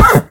Minecraft Version Minecraft Version snapshot Latest Release | Latest Snapshot snapshot / assets / minecraft / sounds / mob / horse / hit2.ogg Compare With Compare With Latest Release | Latest Snapshot